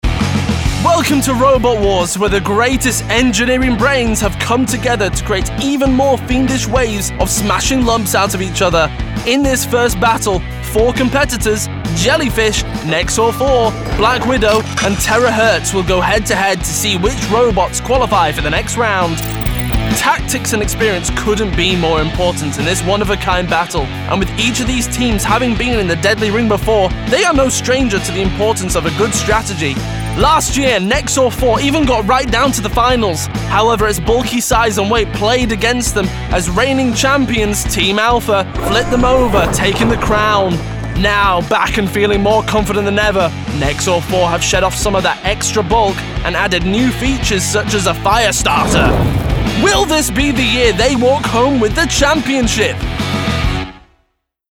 Voice Reel
Robot Wars - Punchy, Fun